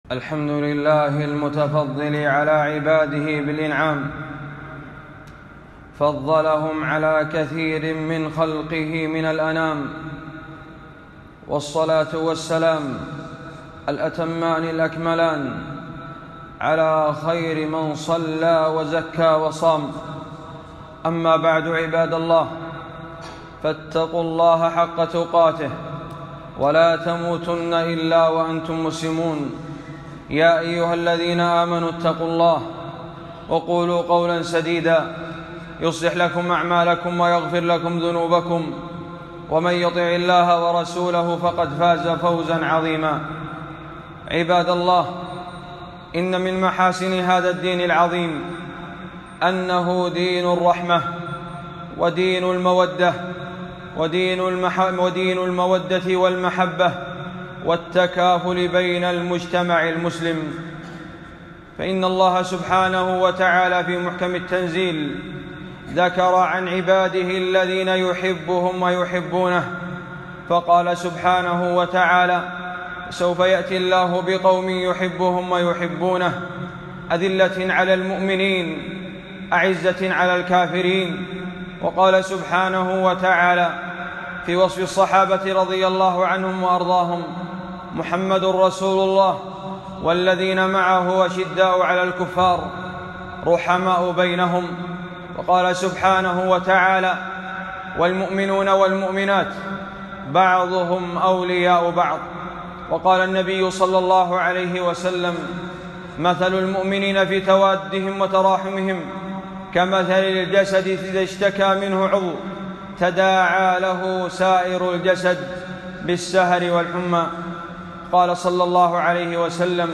خطبة - فضل الصدقة والمتصدقين والحث عليها في وقت الحاجات